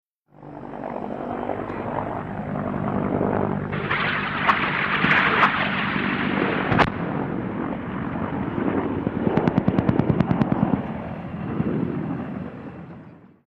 HELICOPTER BELL AH-1 SEA COBRA: EXT: Rocket fires & strikes, distant hovering.